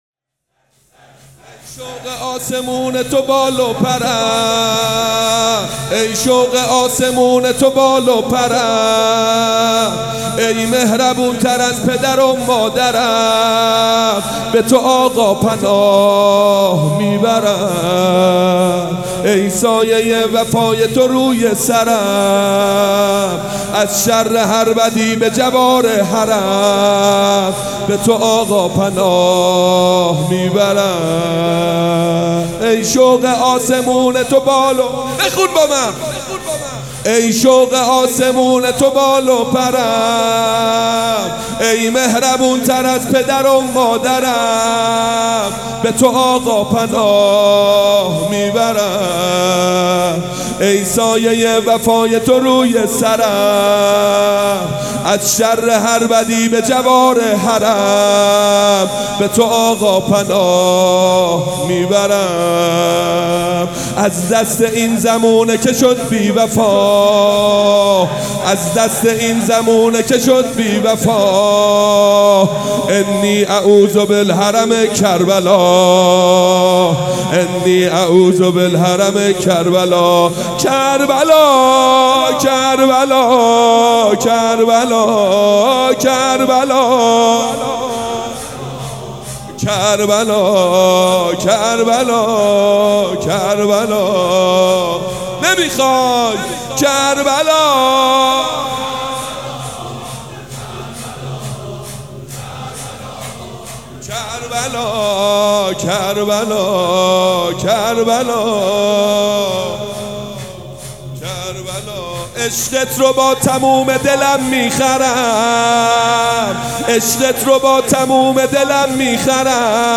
شور
شور دوم.mp3